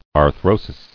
[ar·thro·sis]
Ar*thro"sis , n. [NL., fr. Gr. , fr. joint.] (Anat.) Articulation.